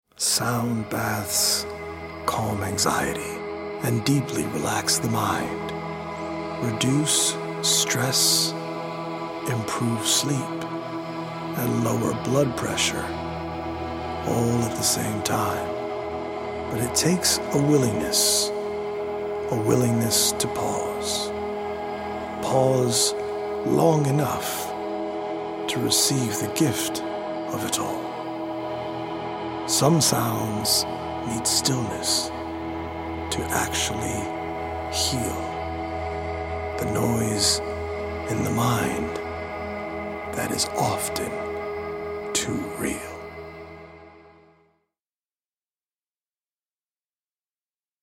100 original poems written/performed
healing Solfeggio frequency music
EDM